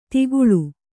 ♪ tiguḷu